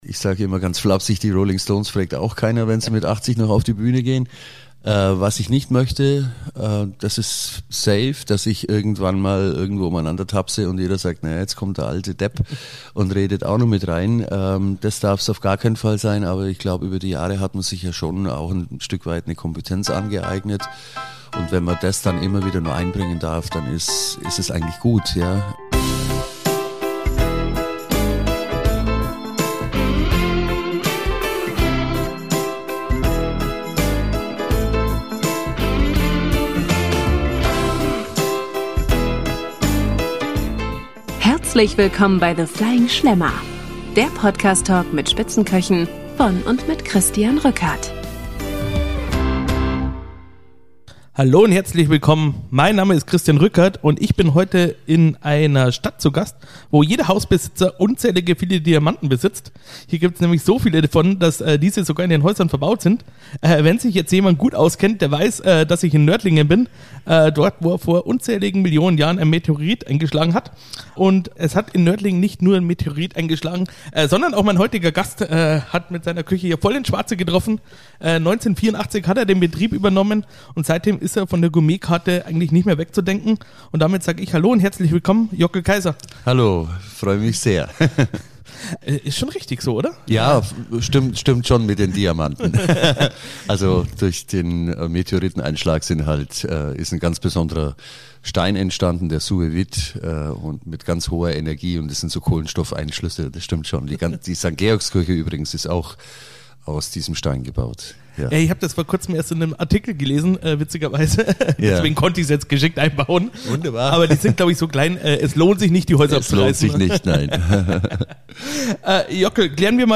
The Flying Schlemmer - Der Podcast Talk mit Sterneköchen